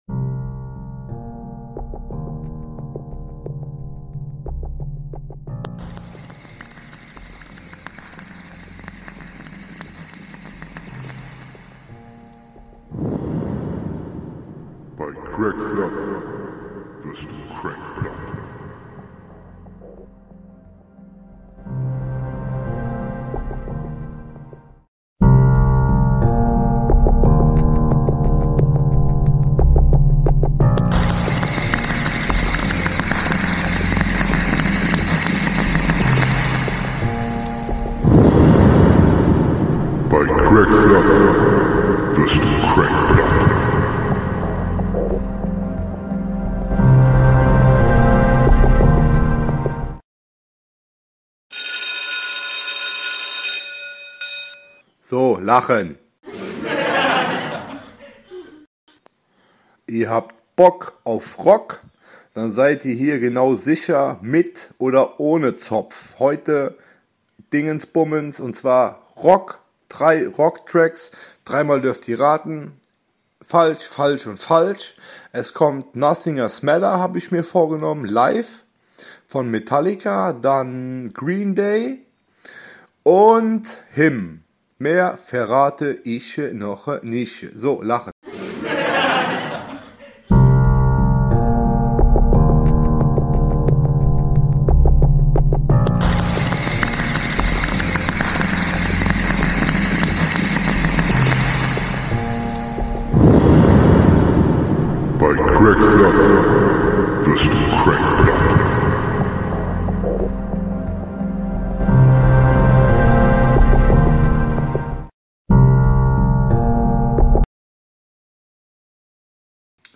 15 MINUTEN ROCK AUF'S OHR - 100%